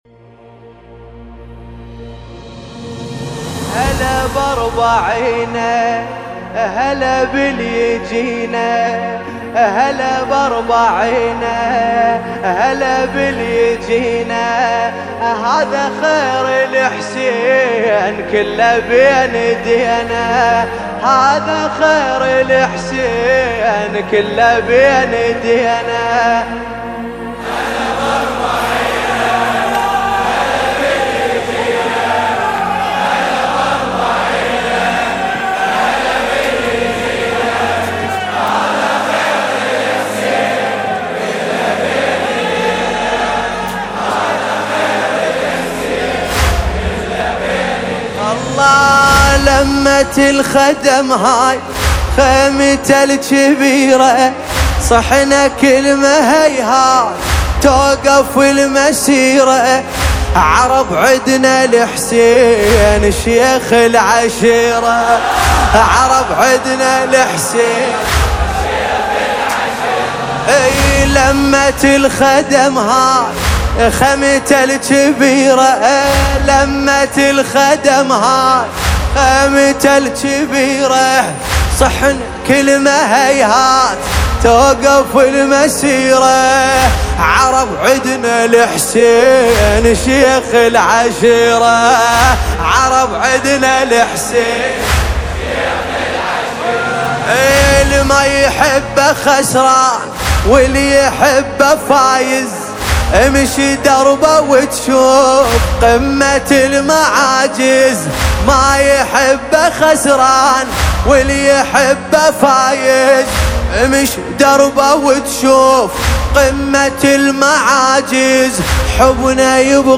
مداحی_اربعین حسینی